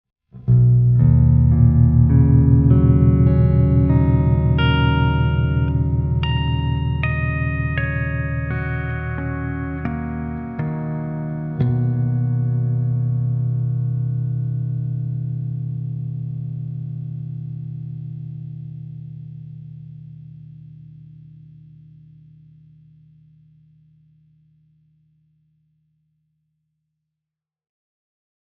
Also – while I could have edited the clips a little tighter – the hum from the CRT is present in the clips so this should be a realistic testing environment of what some one would get just pluging this into their laptop.
C major 9 #11 (if you listen under headphones – you can hear all the harmonics ringing out at the end like a piano with the sustain pedal on) I’m surprised that more people don’t go this route.
c-maj-9-sharp-11-8-string.mp3